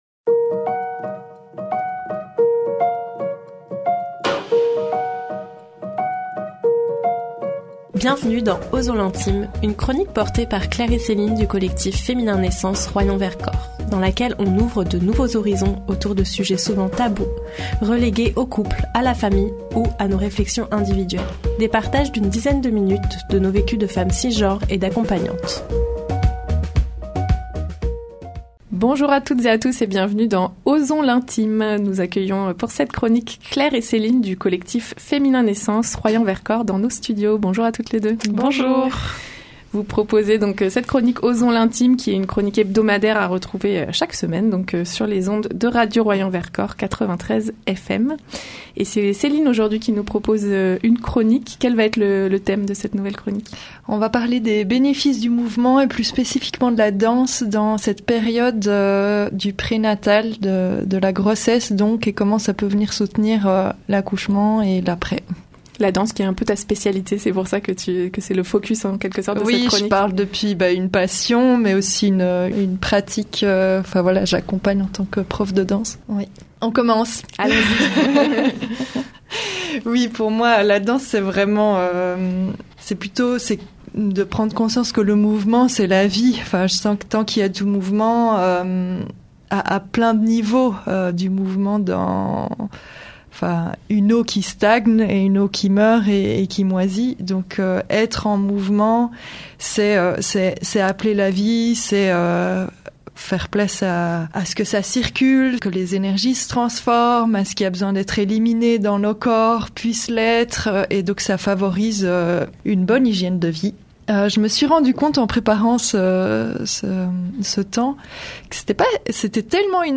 Des partages d’une dizaine de minutes autour de leurs vécus de femmes cisgenres et d’accompagnantes.